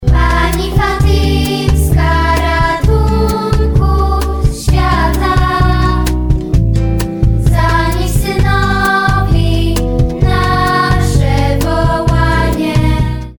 Jest to wersja karaoke